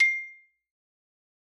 Xylo_Medium_C6_ff_01_far.wav